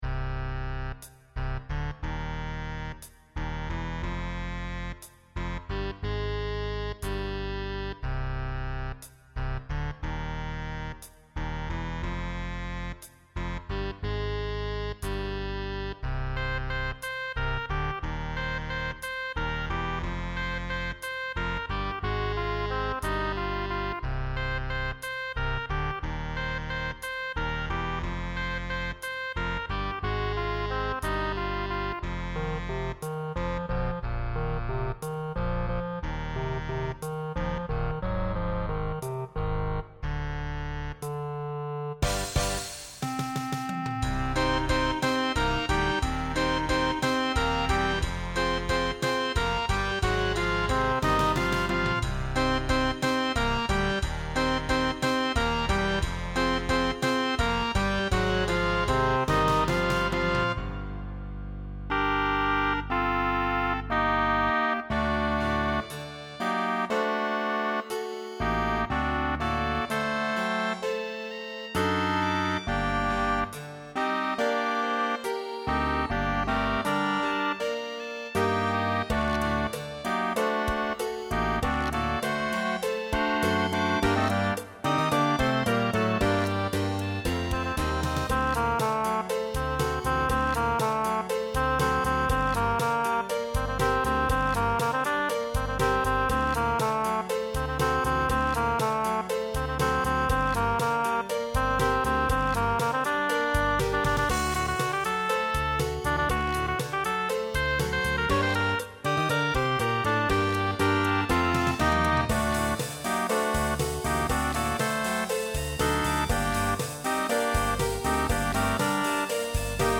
SATB Instrumental combo
Pop/Dance , Swing/Jazz
Mid-tempo